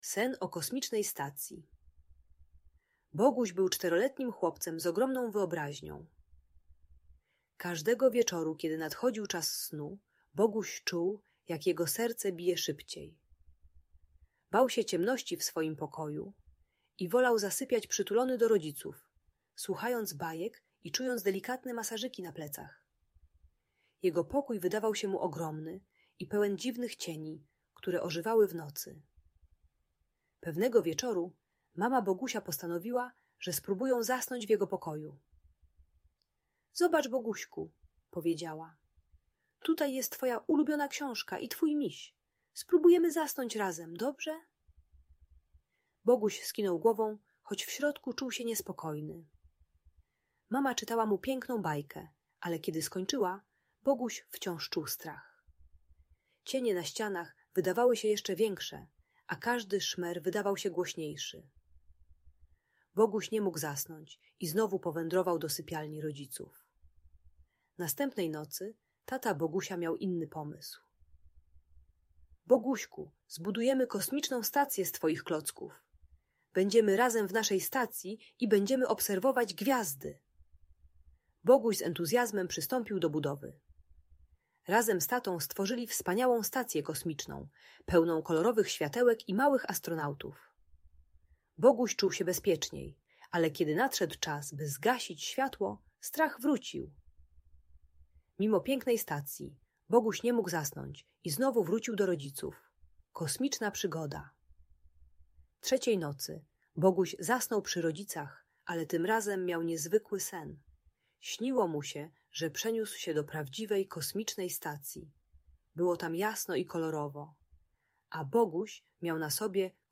Kosmiczna Przygoda Bogusia - Bajka na Dobranoc - Audiobajka